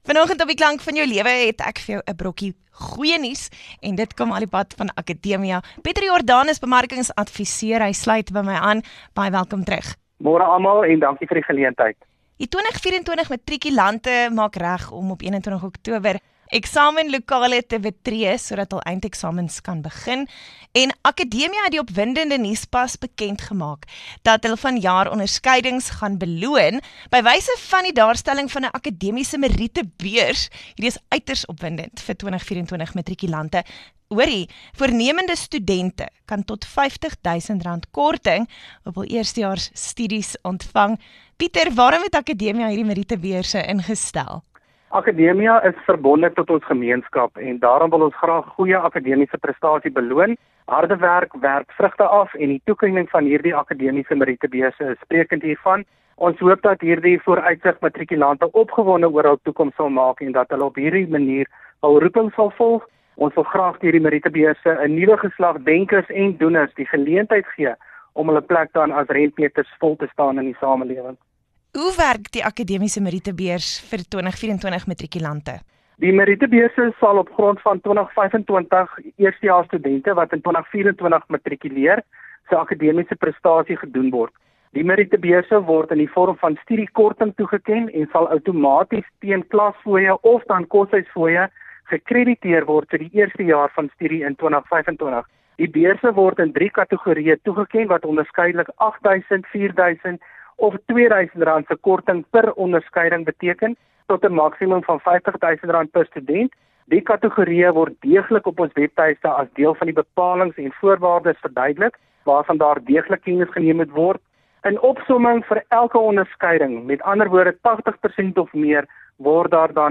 Akademia gaan goeie matriekprestasie beloon by wyse van die daarstelling van akademiese merietebeurse vir matrikulante. Hierdie merietebeurse sal van toepassing wees op die instelling se 2025- akademiese jaar. Luister na die onderhoud om meer uit te vind.